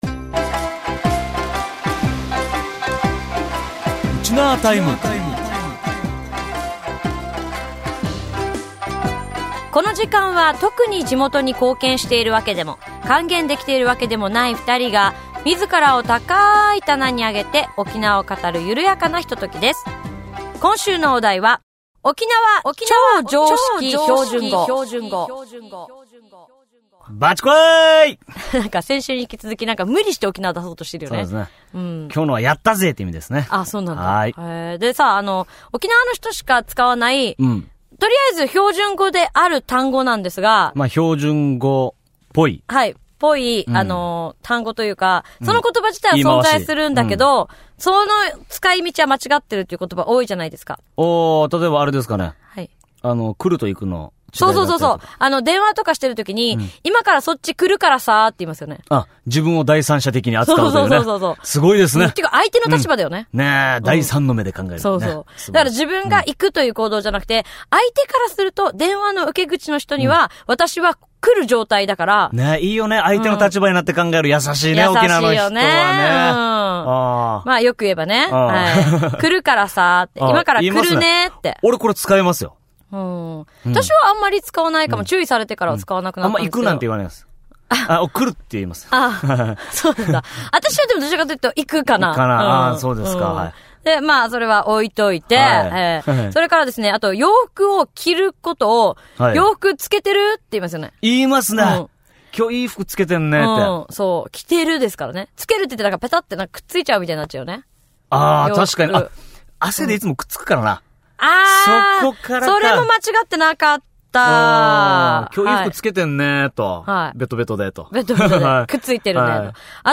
地元沖縄トーク♪